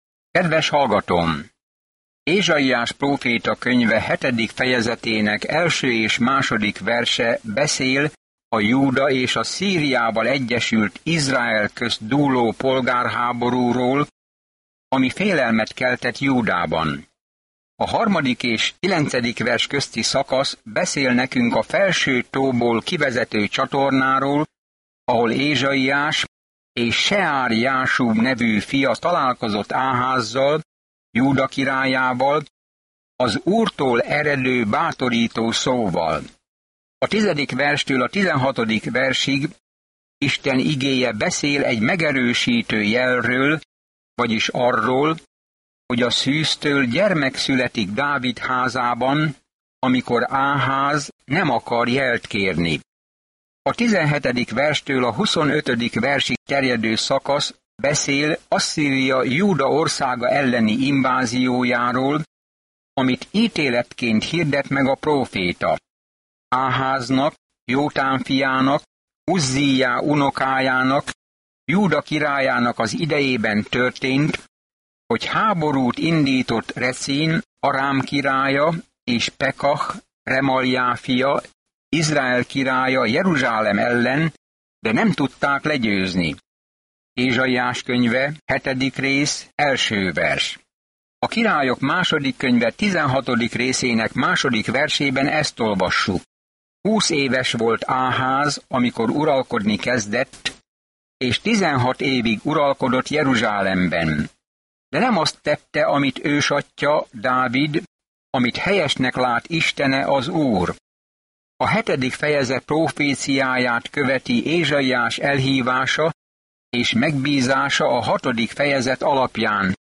Szentírás Ézsaiás 7:1-14 Nap 8 Olvasóterv elkezdése Nap 10 A tervről Az „ötödik evangéliumnak” nevezett Ézsaiás egy eljövendő királyt és szolgát ír le, aki „sokak bűnét viseli” egy sötét időben, amikor a politikai ellenségek utolérik Júdát. Napi utazás Ézsaiáson keresztül, miközben hallgatod a hangos tanulmányt, és Isten szavából válogatott verseket olvasol.